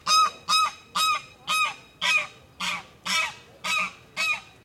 Add goose sound
sounds_goose.ogg